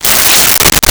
Cloth Rip
Cloth Rip.wav